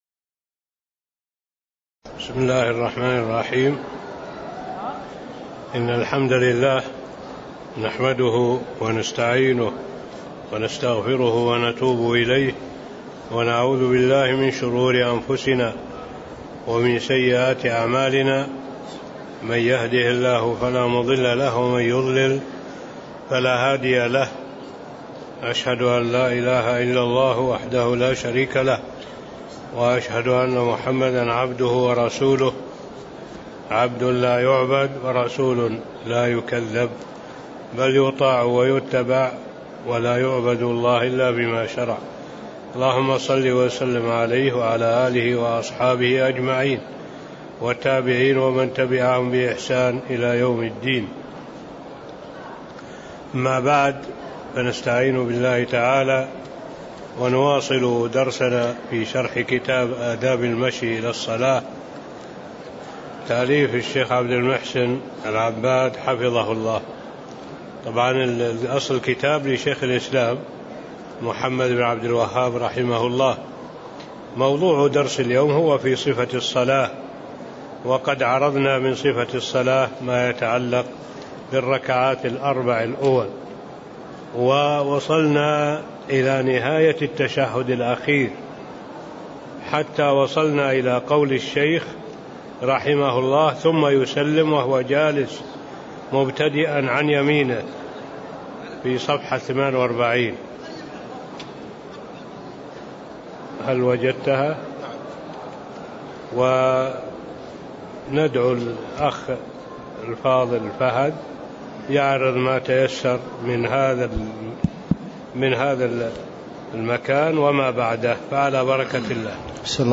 تاريخ النشر ١٦ صفر ١٤٣٦ هـ المكان: المسجد النبوي الشيخ: معالي الشيخ الدكتور صالح بن عبد الله العبود معالي الشيخ الدكتور صالح بن عبد الله العبود باب صفة الصلاة (11)